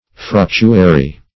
Search Result for " fructuary" : The Collaborative International Dictionary of English v.0.48: Fructuary \Fruc"tu*a*ry\ (fr[u^]k"t[-u]*[asl]*r[y^]), n.; pl.
fructuary.mp3